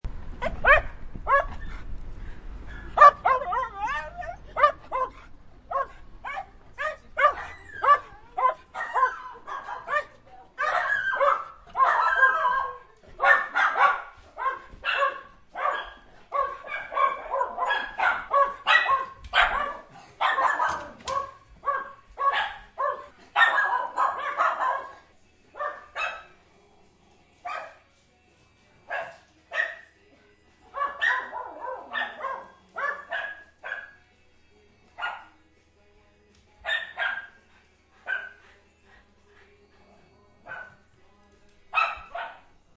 Schnauzer Greeting Sound Button - Free Download & Play
Dog Sounds875 views